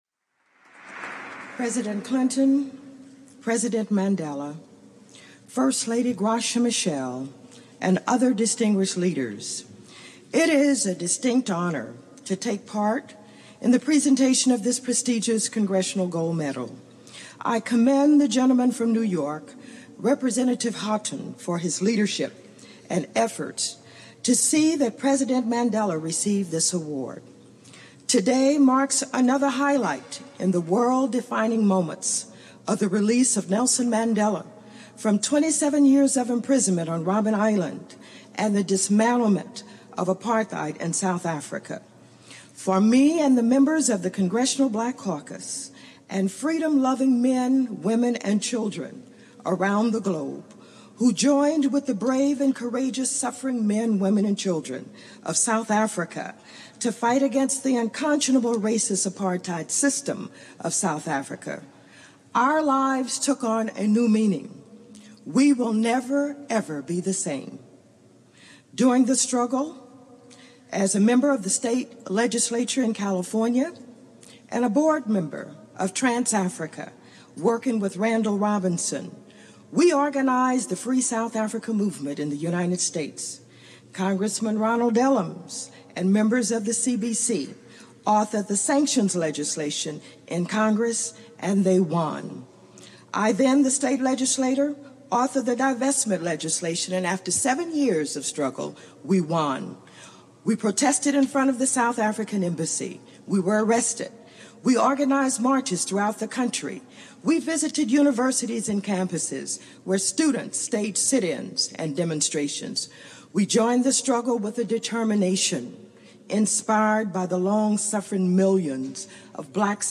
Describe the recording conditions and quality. Address at the Congressional Gold Medal Ceremony for Nelson Mandela